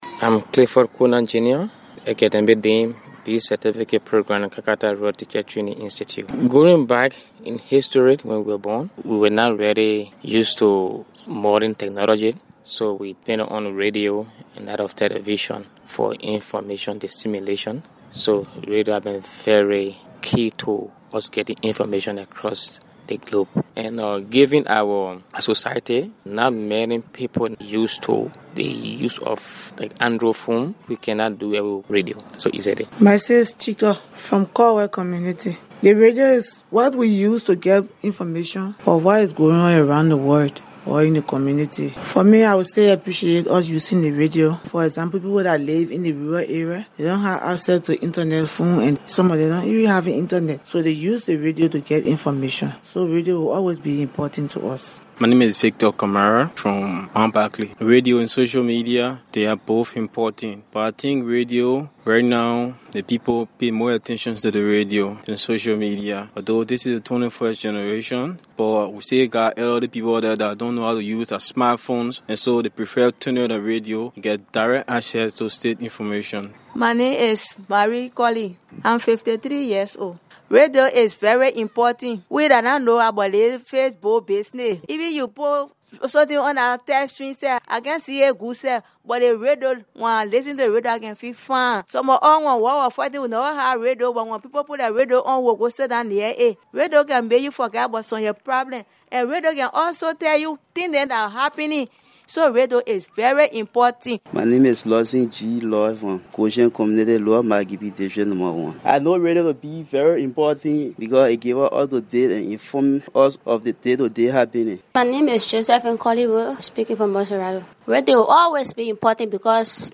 asked some Liberians to share their views about radio.